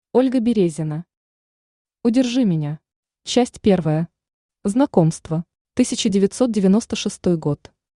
Аудиокнига Удержи меня | Библиотека аудиокниг
Aудиокнига Удержи меня Автор Ольга Березина Читает аудиокнигу Авточтец ЛитРес.